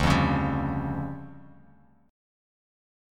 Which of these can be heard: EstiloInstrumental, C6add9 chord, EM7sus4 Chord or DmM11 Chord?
DmM11 Chord